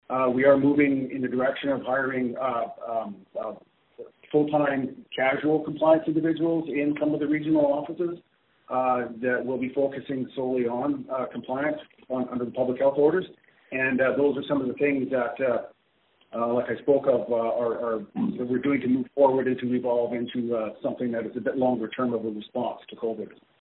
Conrad Baet,  sous-administrateur en chef de la santé publique des T.N.-O. :